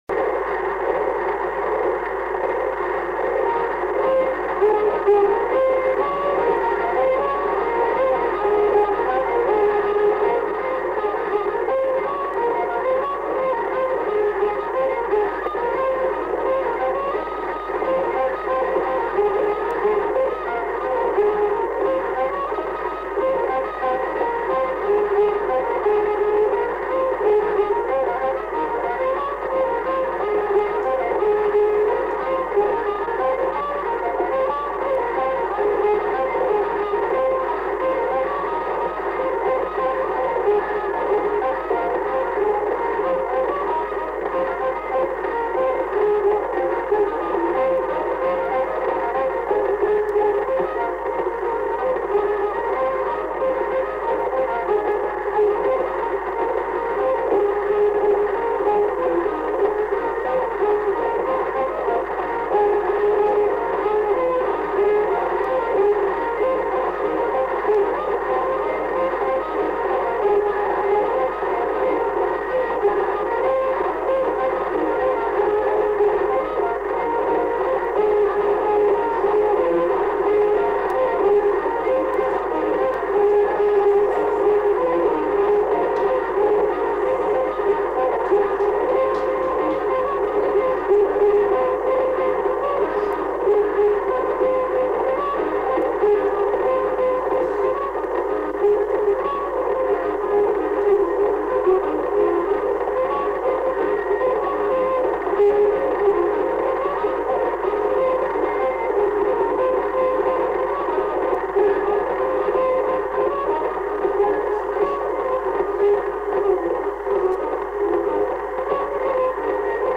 Aire culturelle : Béarn
Lieu : Bielle
Genre : morceau instrumental
Instrument de musique : violon